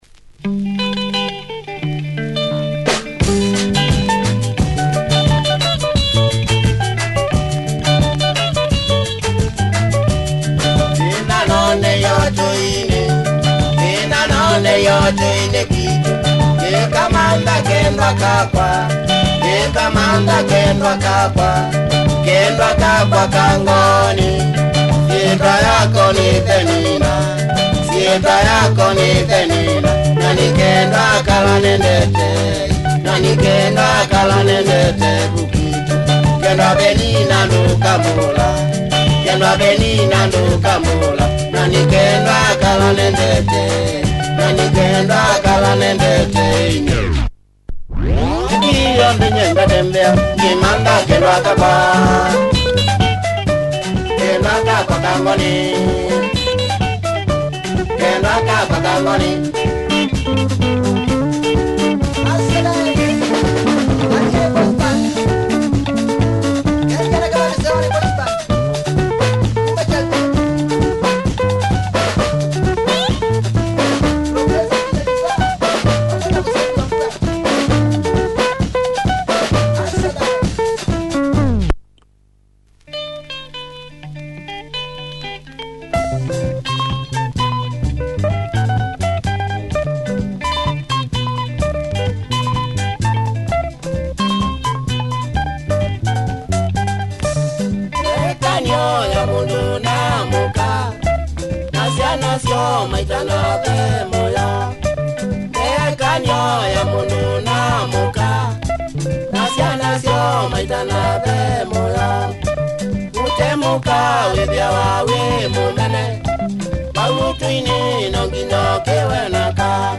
Heavy Kikamba benga.